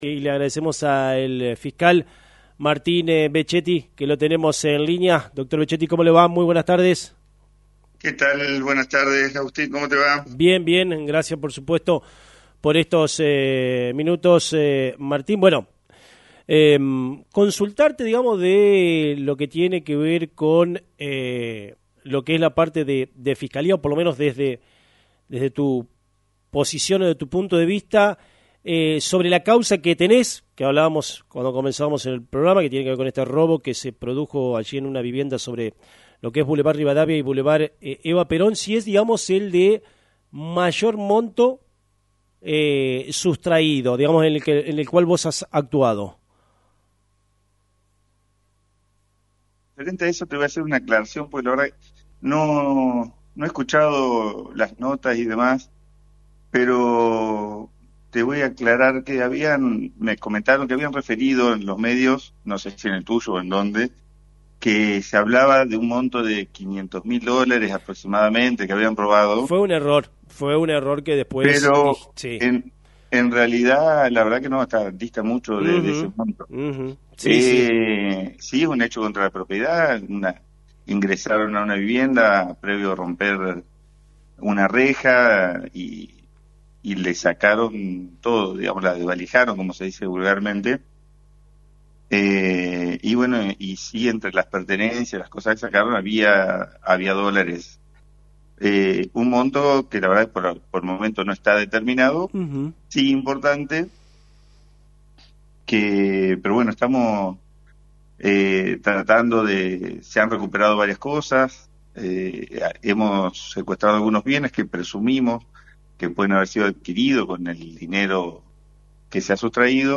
Avances en la Investigación del Robo en Bv. Rivadavia y Bv. Eva Perón: Entrevista con el Fiscal Martín Vechetti – Lt39 Noticias
Fiscal-Martin-Vechetti.mp3